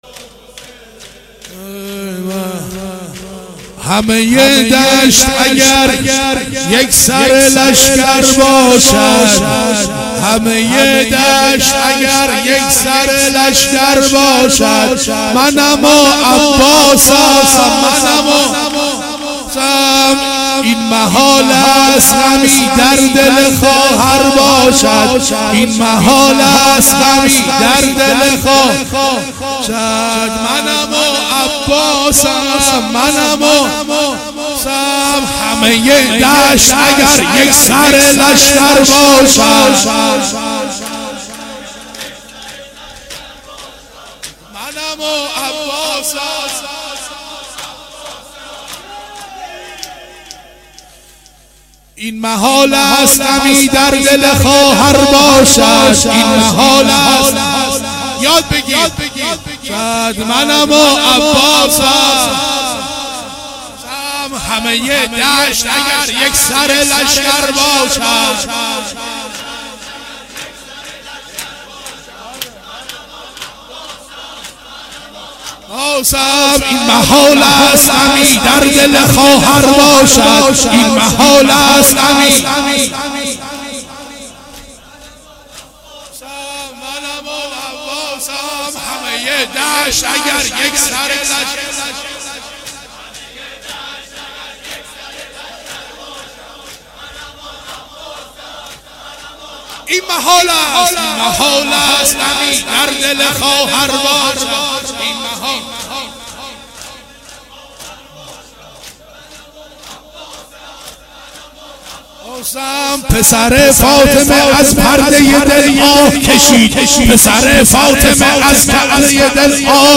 دودمه شب دوم محرم 96